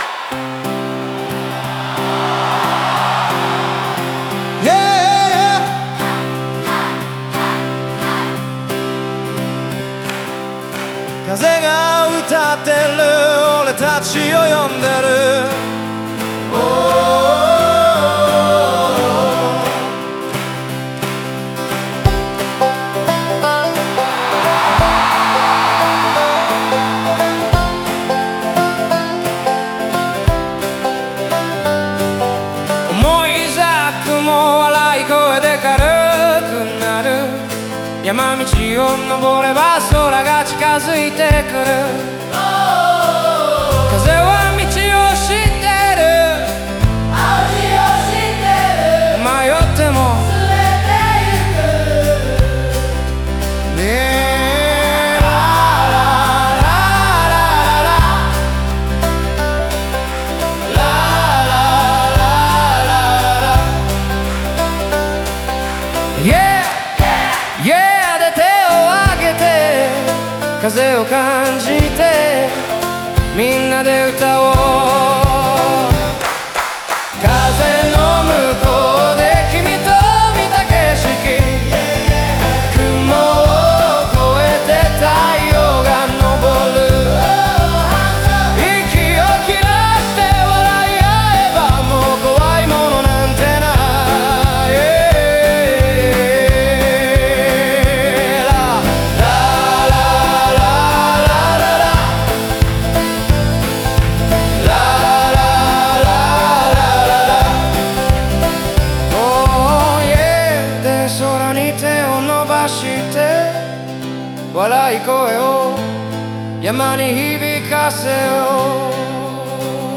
短くシンプルなフレーズとコール＆レスポンスが、ライブの盛り上がりと親しみやすさを生んでいます。